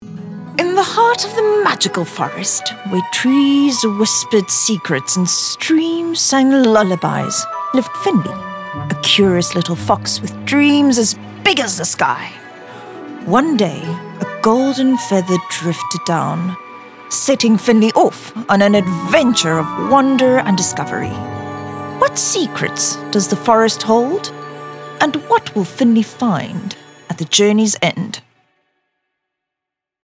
authoritative, dramatic, elegant, soothing
Elegant, Sophisticated, and sometimes, just a little bit silly.
Finley the Fox Character Story Narration